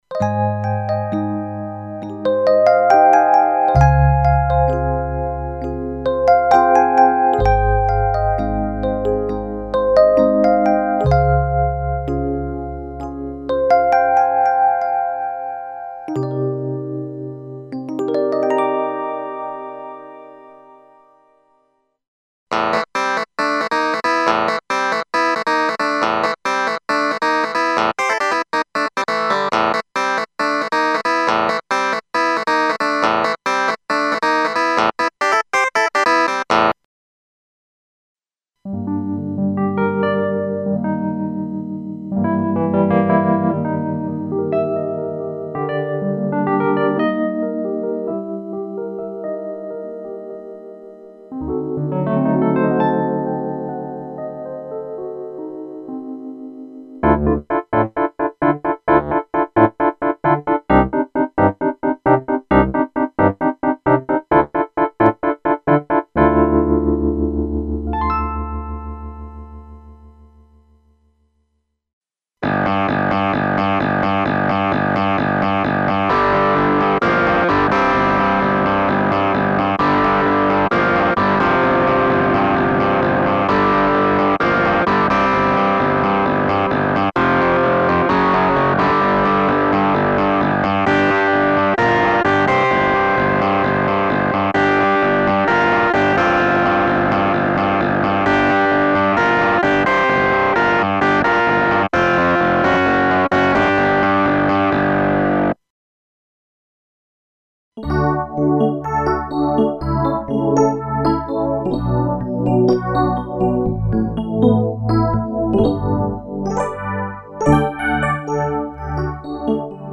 Exclusive collection of clavinet and electric piano sound programs (Rhodes, Wurlitzer, Yamaha CP80, Hohner Pianet and FM piano emulations) including a large number of specially modulated (filter, shaper, distortion, etc.) clavinet and electric piano sounds, carefully designed for various music styles.